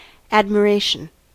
Ääntäminen
IPA : /ˌæd.mɚˈeɪ.ʃən/